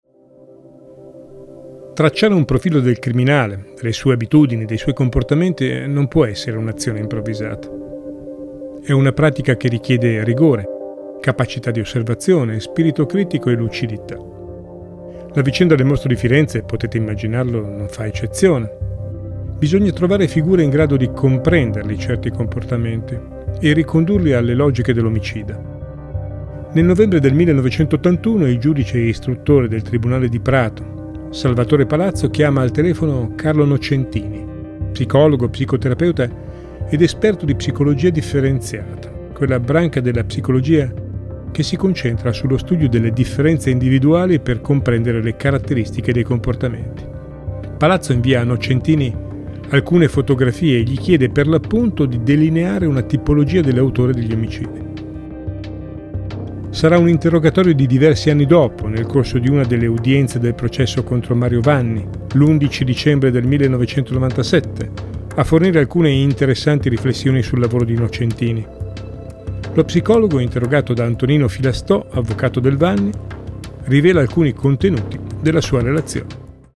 Musiche originali Operà Music